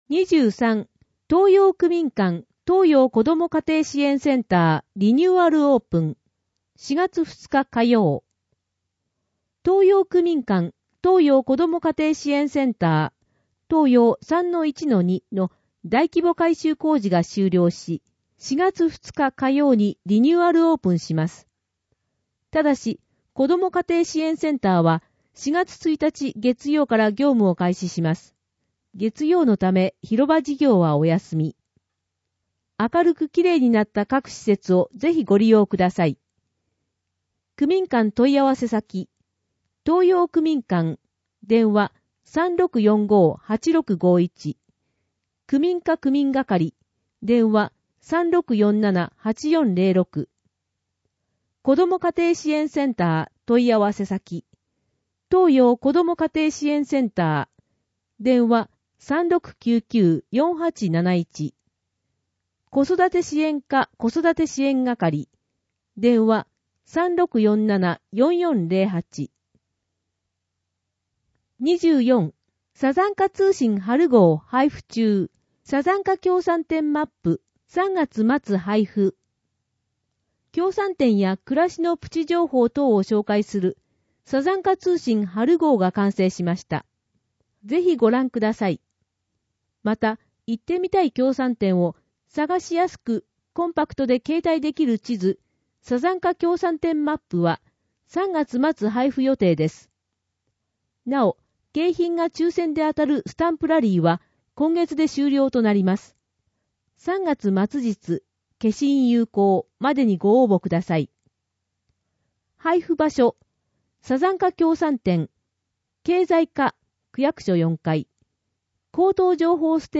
また、関連リンクに、区報の音声ファイルも掲載していますので、ご利用ください。